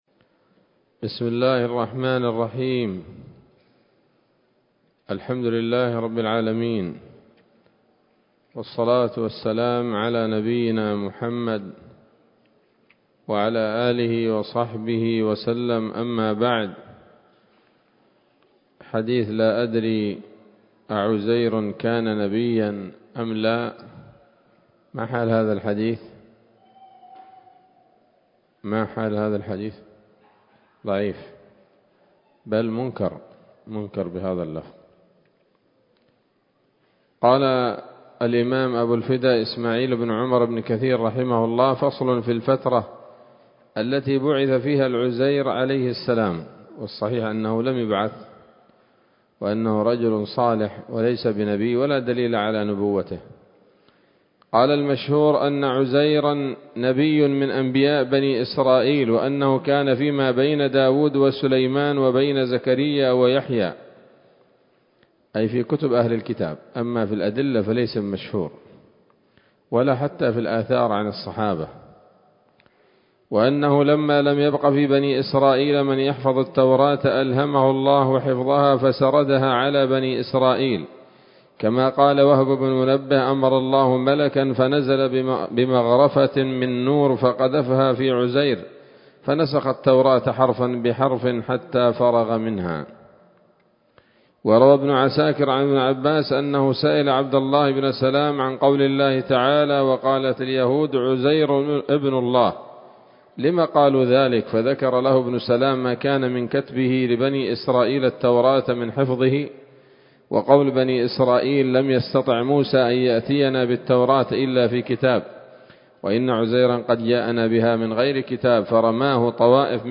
‌‌الدرس الثاني والثلاثون بعد المائة من قصص الأنبياء لابن كثير رحمه الله تعالى